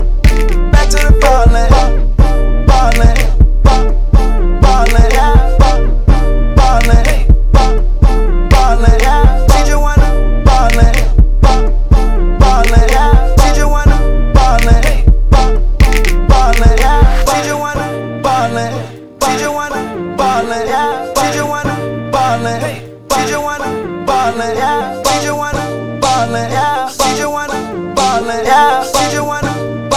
House Dance
Жанр: Танцевальные / Хаус